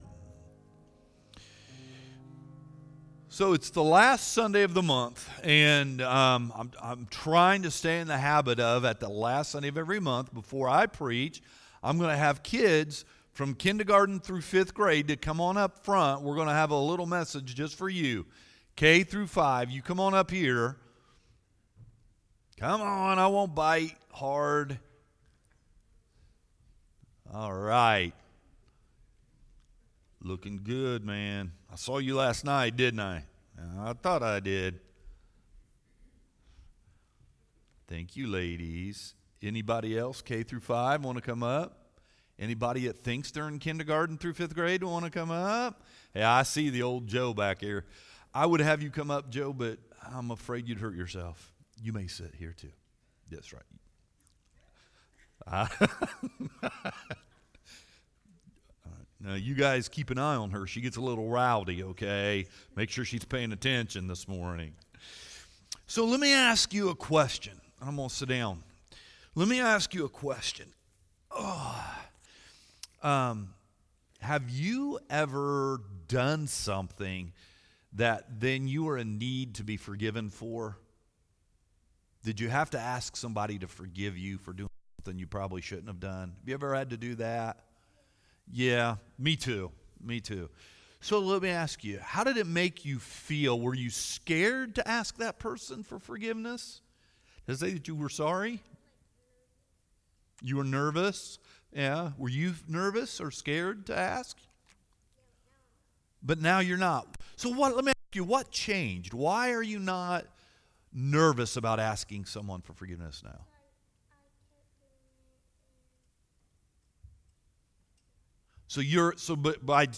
Sermons | Old Town Hill Baptist Church